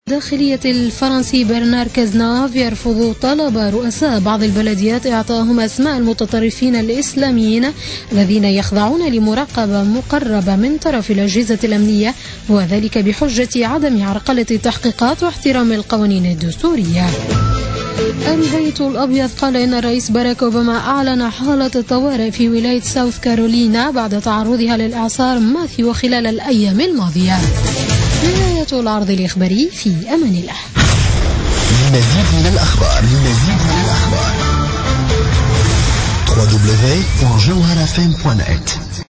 نشرة أخبار منتصف الليل ليوم الاربعاء 12 أكتوبر 2016